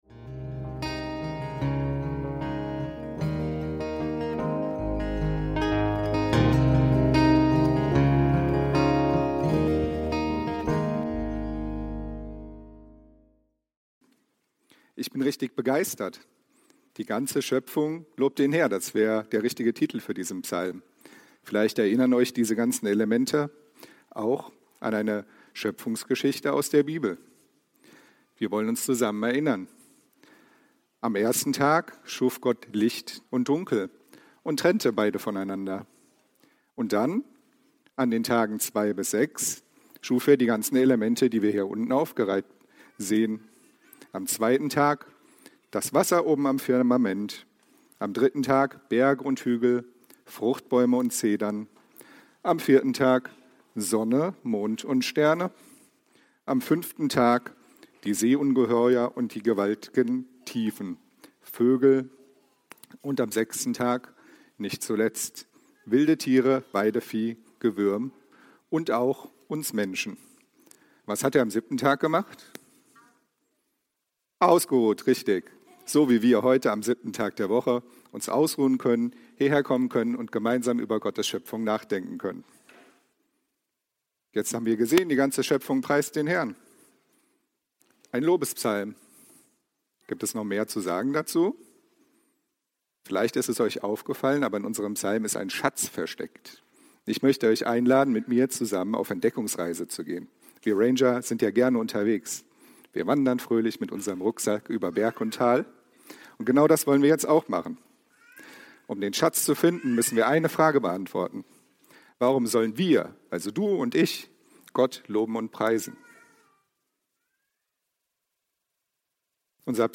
Leben in der Schöpfungsgemeinschaft – Anbeten: Wie die Natur die Herrlichkeit Gottes preist (Psalm 148) ~ FeG Bochum Predigt Podcast